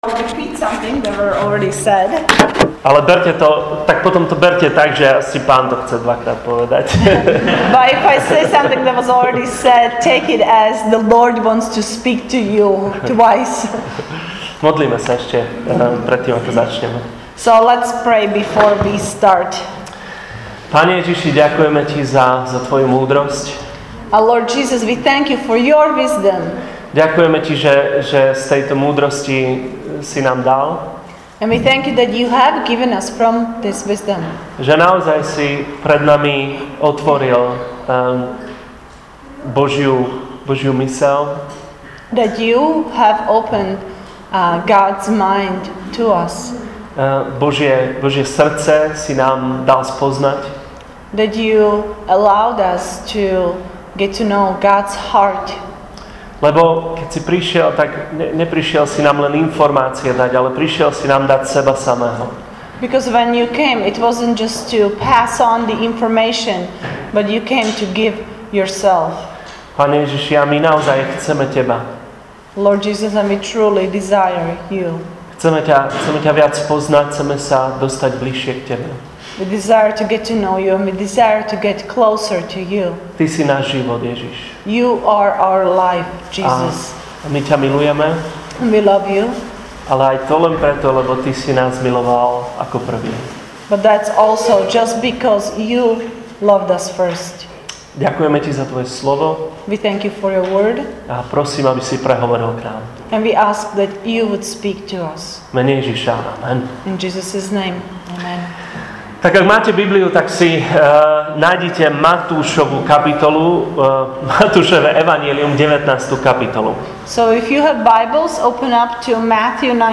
“LOVE IS” is our current Sunday morning teaching series: 6 weeks, multiple teachers, exploring everything from True Love, Compassion, Sex, Friendship, and finding the One.